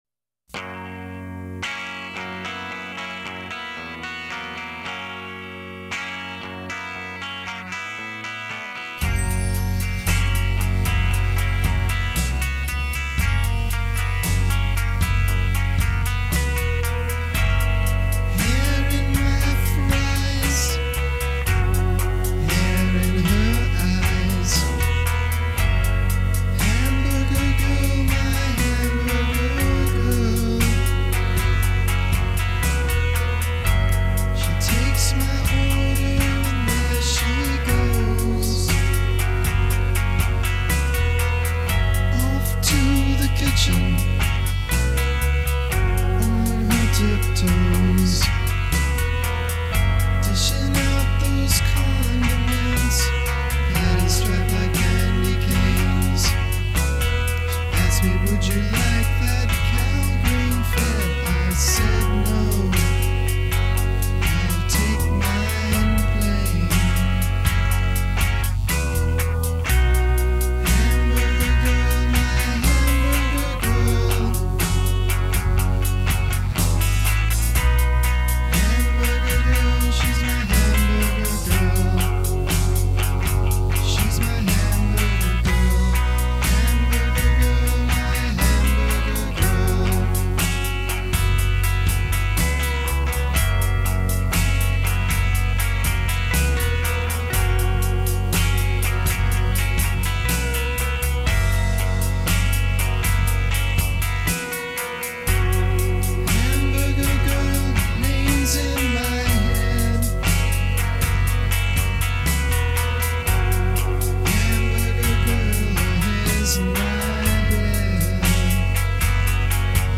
A more low-key song from the first album.